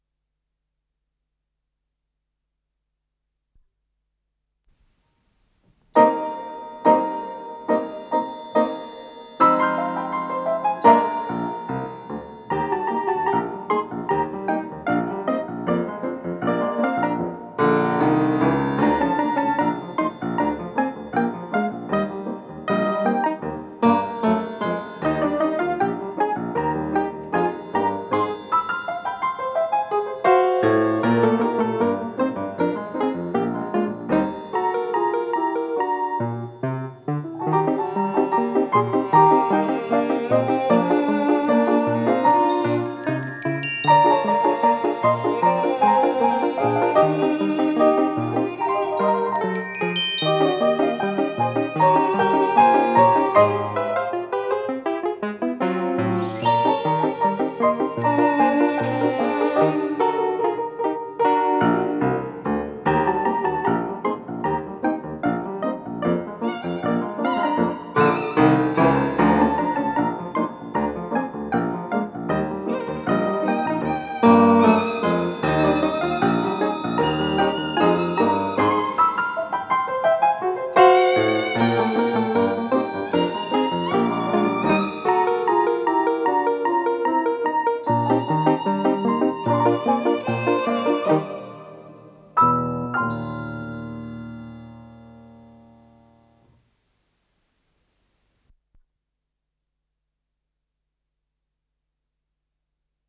с профессиональной магнитной ленты
ПодзаголовокЗаставка, ми бемоль мажор